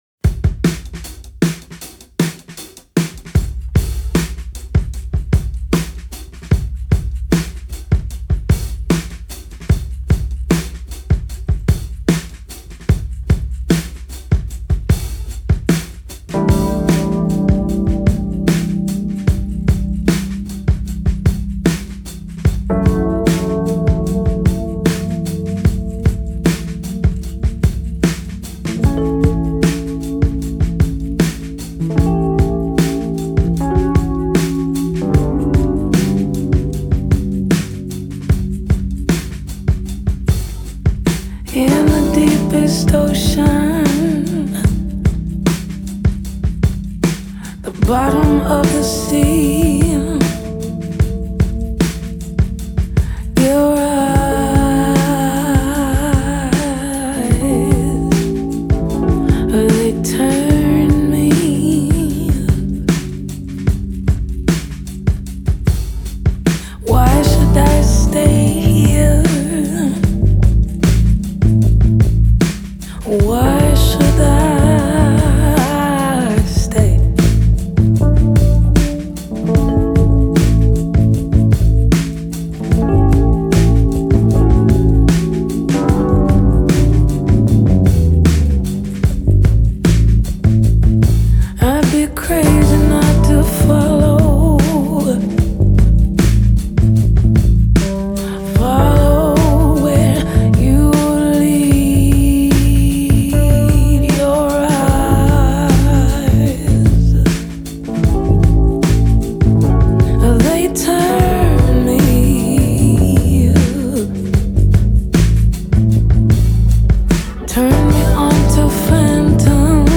feels like you are swimming under water in the ocean